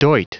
Prononciation du mot doit en anglais (fichier audio)
Prononciation du mot : doit